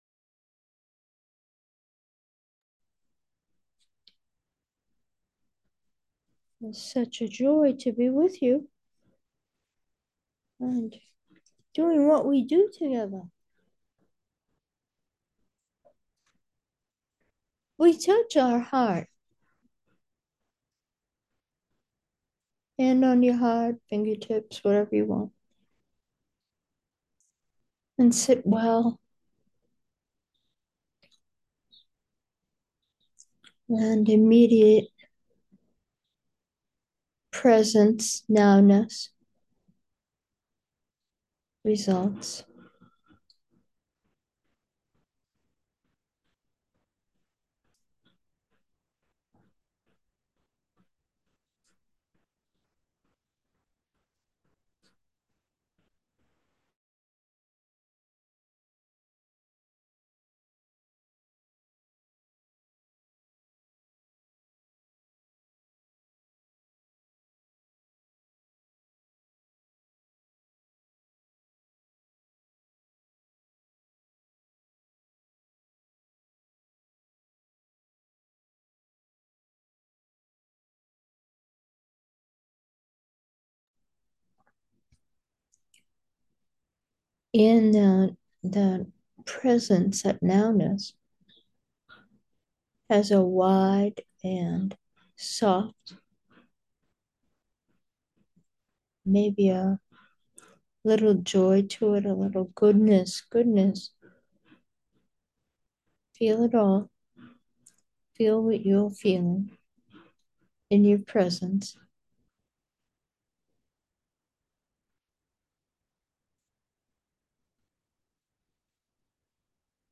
Meditation: happy heart